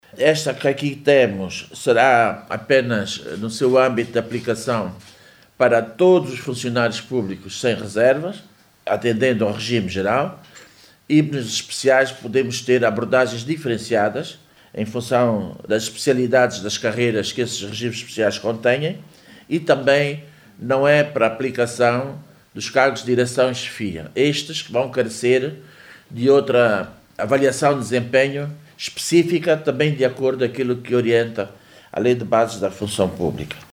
A ministra Teresa Dias esclarece que a avaliação será feita a cada seis meses, obedecendo a critérios objectivos de acordo com os interesses da administração pública.